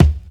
KICK_STRING_REV.wav